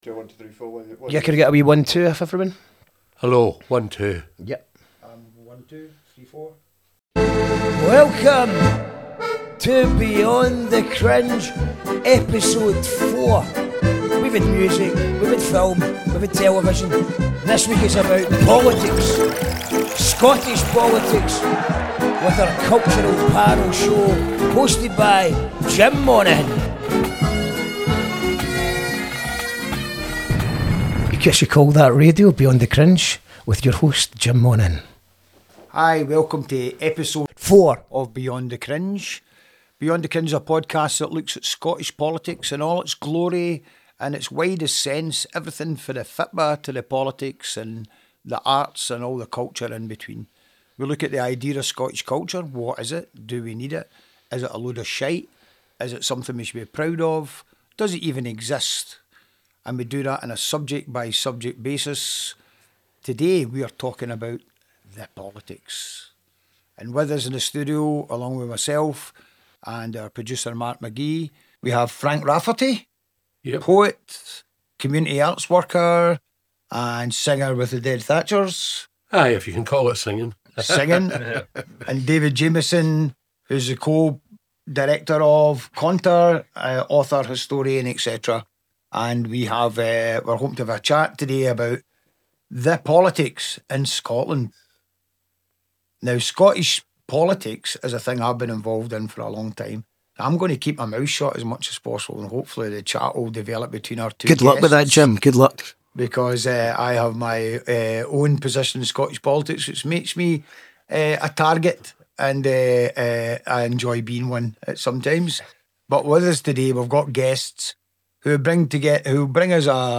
Beyond the Cringe is a panel show where we analyse Scottish Culture.